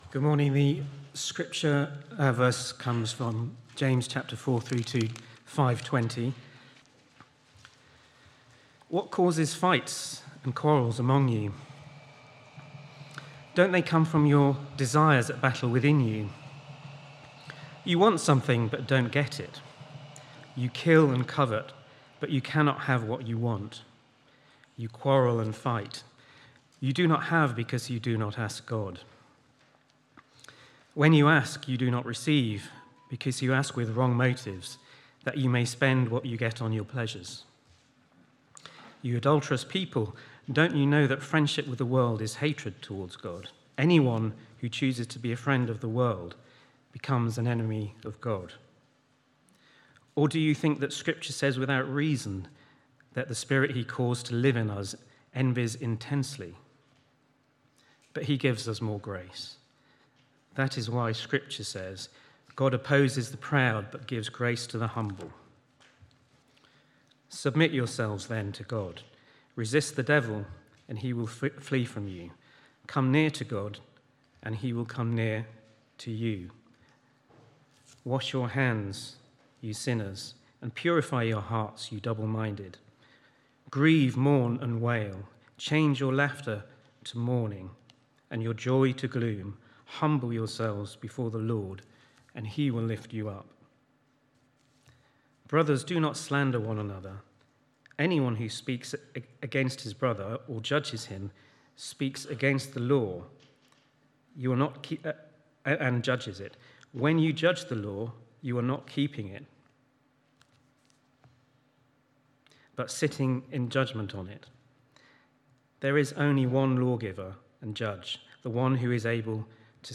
Media Library Media for Sunday Service on Sun 20th Jul 2025 10:00 Speaker
James 4:1-5:20 Series: James: Faith in Action - Real Faith, Real Life. Theme: Living in Humility and Prayer Sermon To find a past sermon use the search bar below You can search by date, sermon topic, sermon series (e.g. Book of the Bible series), bible passage or name of preacher (full or partial) .